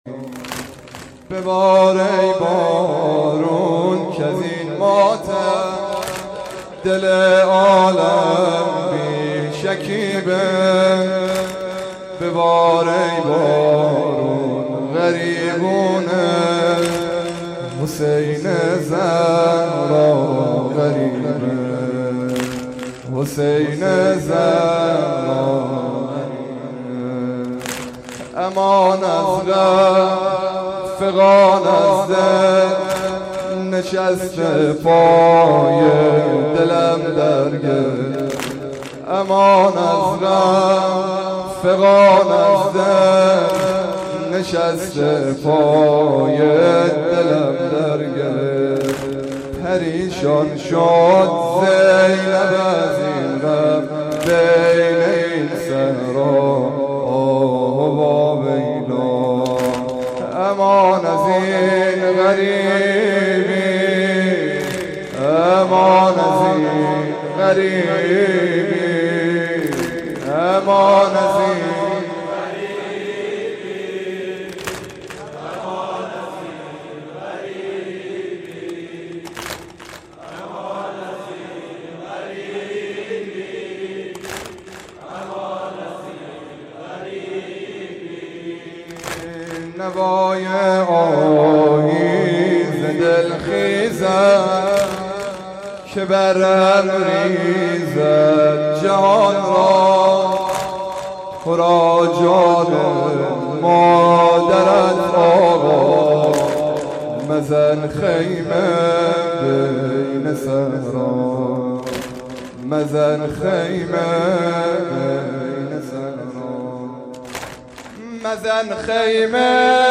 شب دوم محرم 93